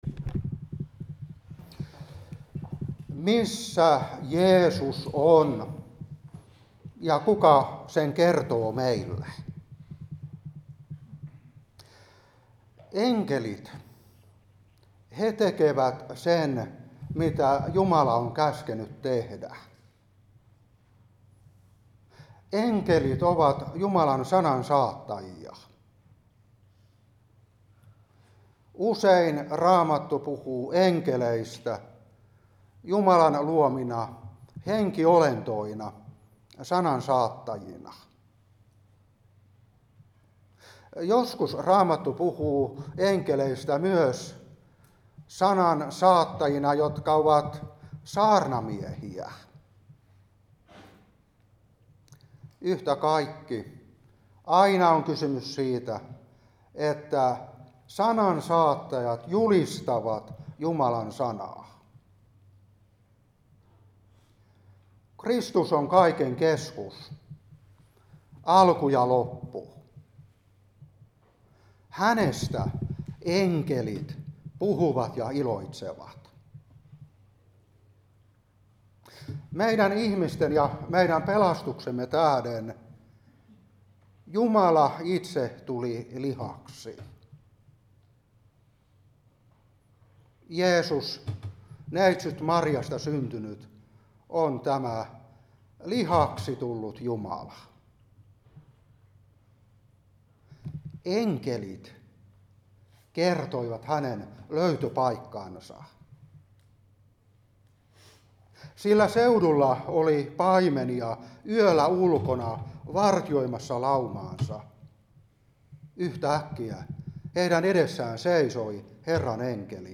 Opetuspuhe 2025-4. Luuk.2:8-11; 24:1-8. Ilm.3:11; 14:16-17.